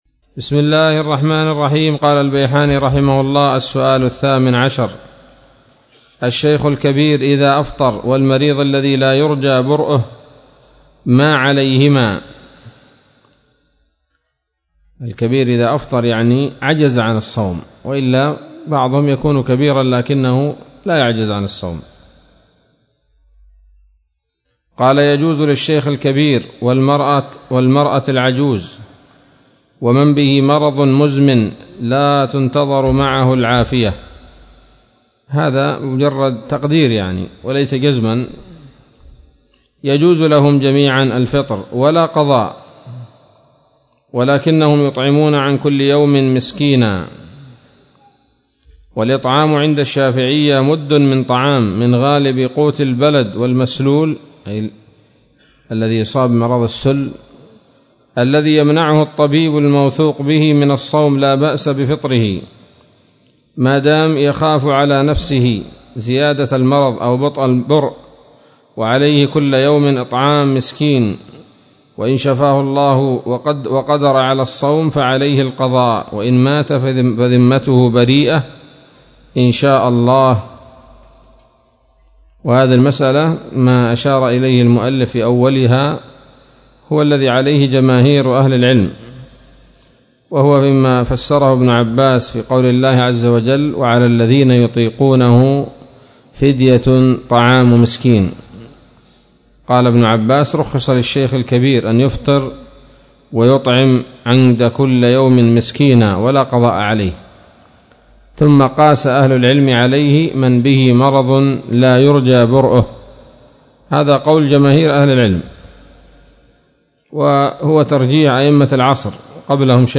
الدرس السادس عشر من تحفة رمضان للعلامة البيحاني